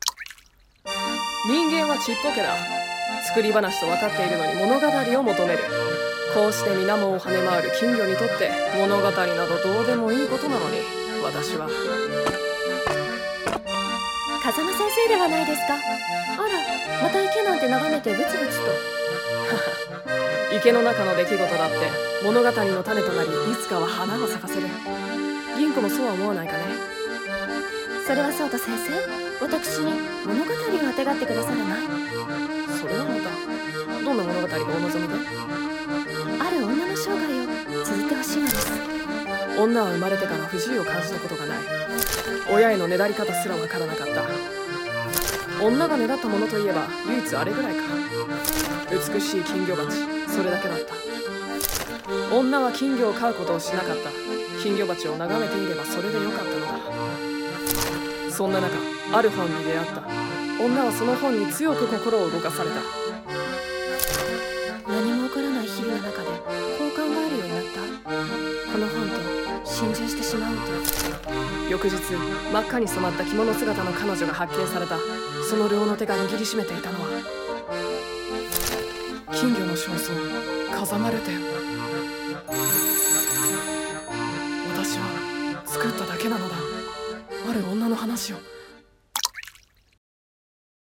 【声劇】大正物書金魚叙情